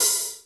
HR16B HHOP 3.wav